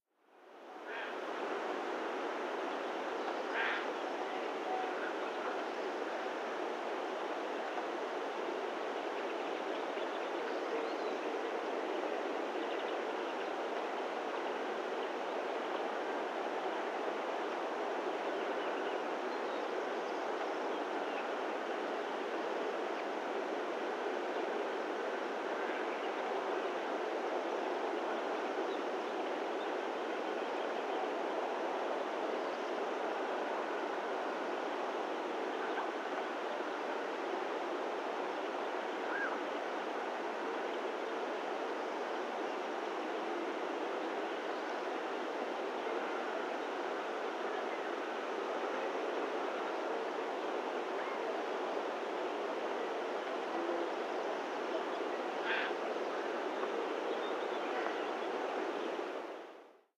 Ambiente de bosque navarro
Sonidos: Animales Sonidos: Rural